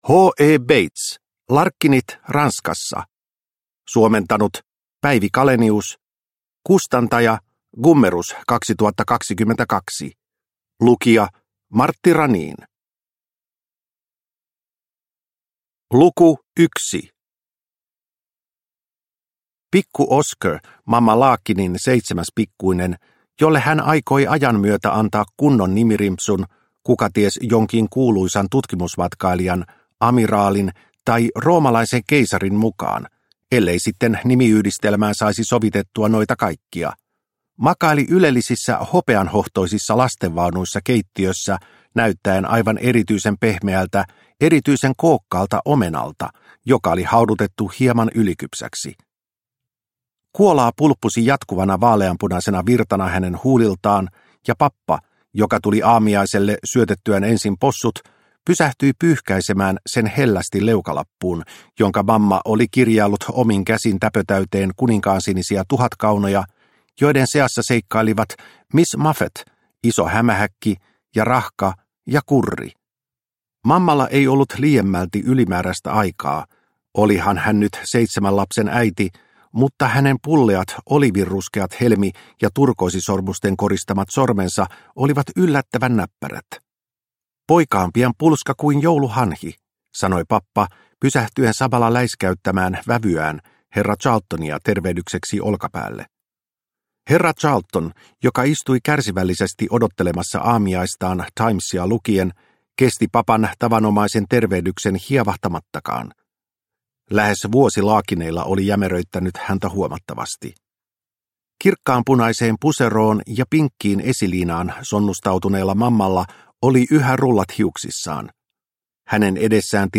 Larkinit Ranskassa – Ljudbok – Laddas ner